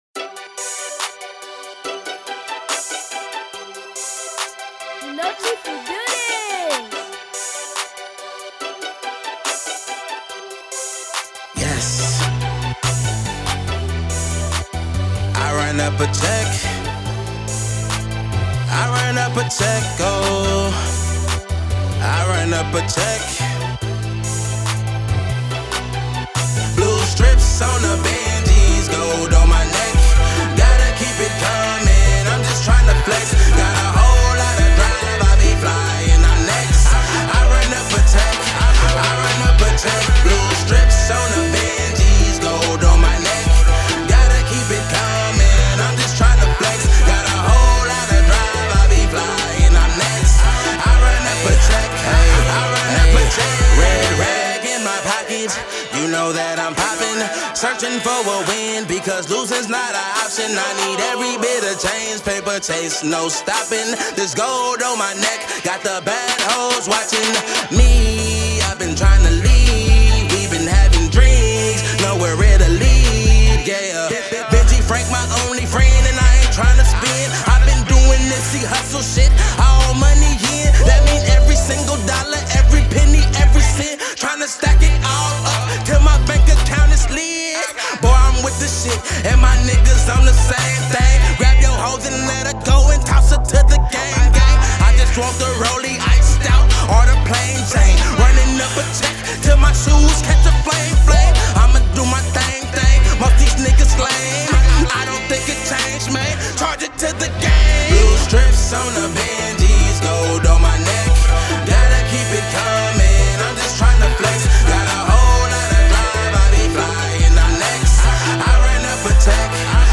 Hiphop
is a energized record for a good or bad day